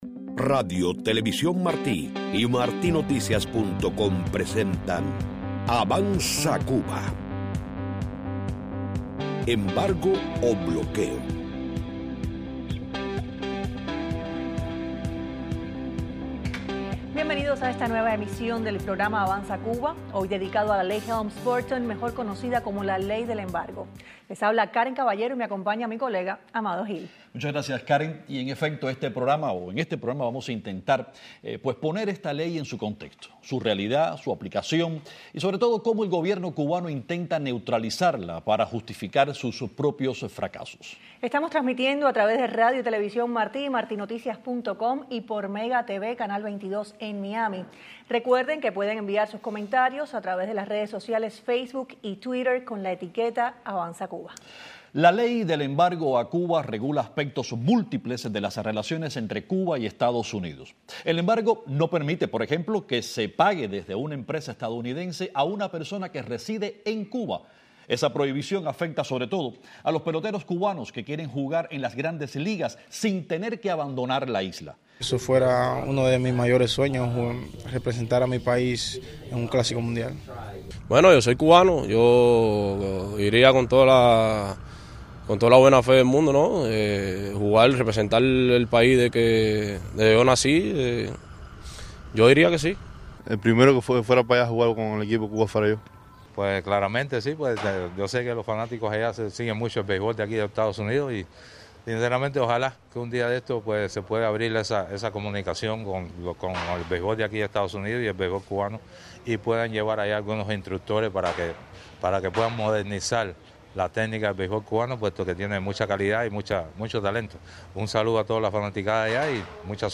Para este análisis han invitado al programa reporteros, abogados, profesores y analistas de renombre.